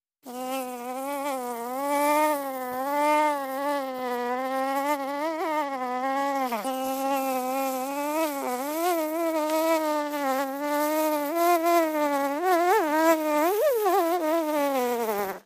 Animals-insects Single|Flies | Sneak On The Lot